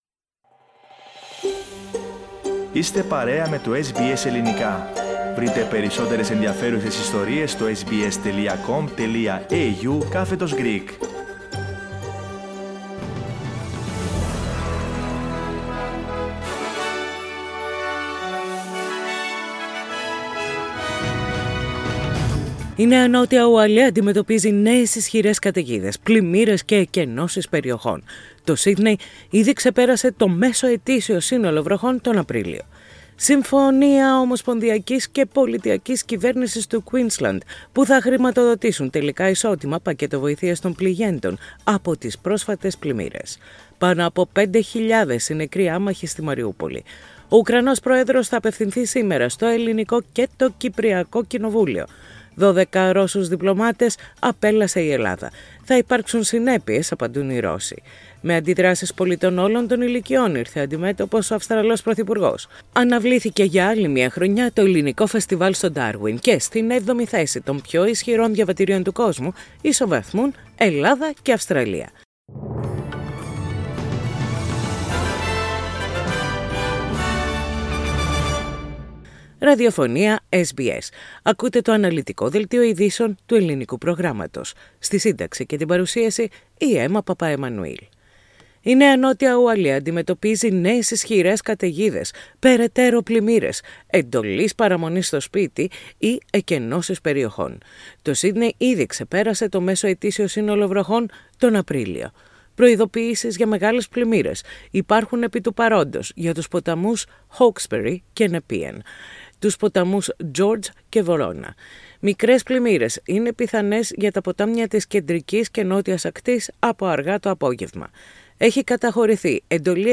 The detailed bulletin of the day, with the main news from Australia, Greece, Cyprus and the international field.